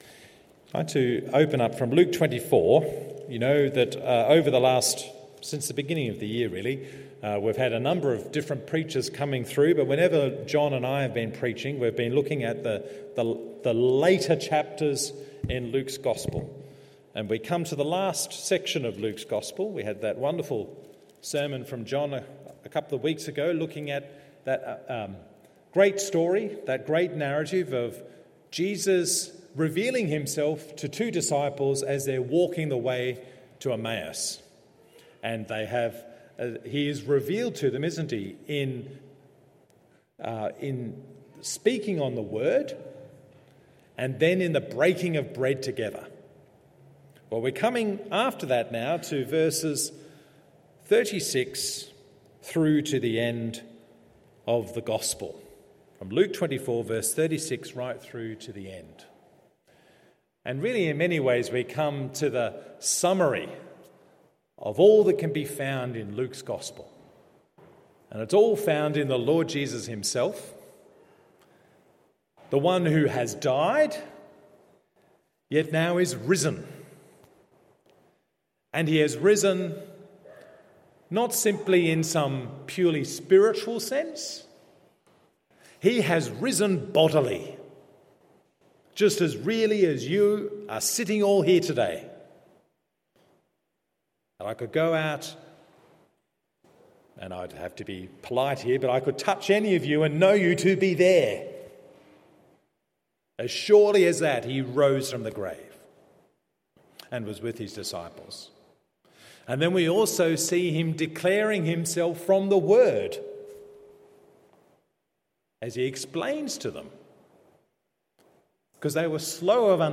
Morning Service Luke 24:36-53…